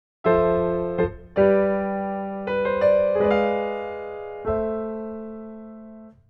Rootless Dom9 (Passing Diminished)